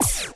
weapon_player.wav